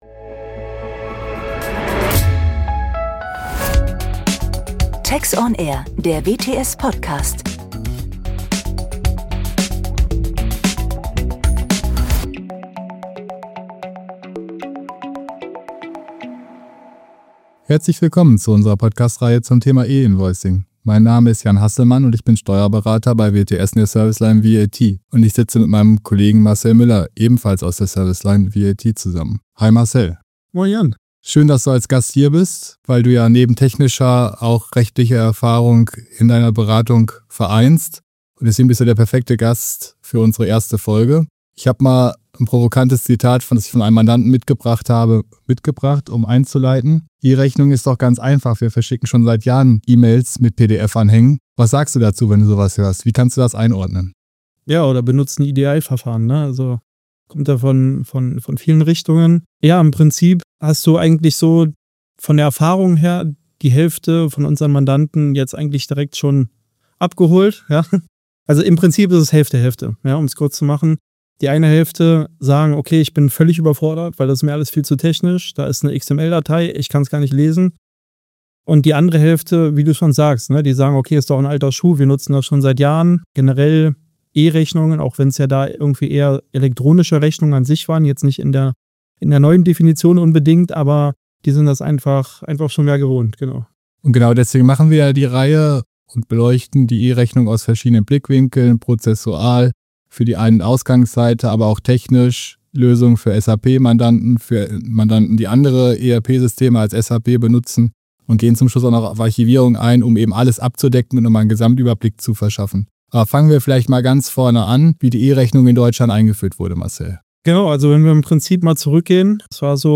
Die beiden Experten gehen in einem lockeren Gespräch auf die fachlichen Herausforderungen sowie aktuelle (technische) Fragestellungen ein.